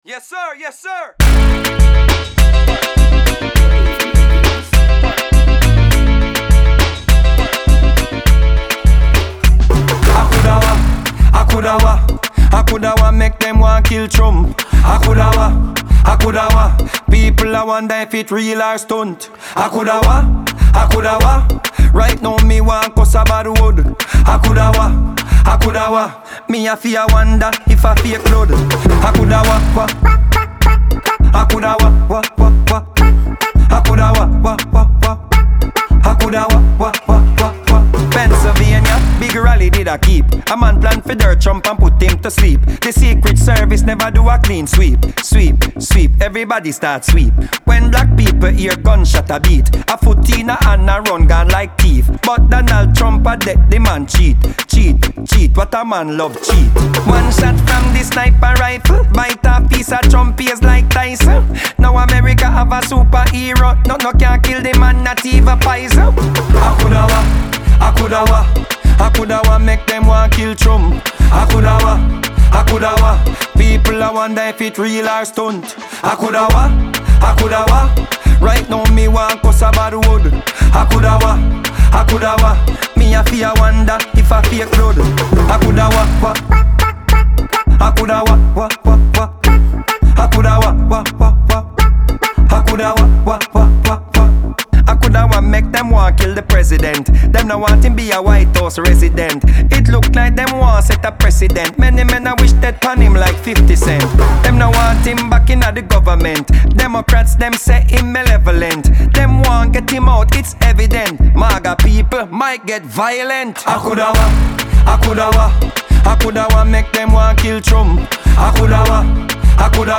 Dancehall Single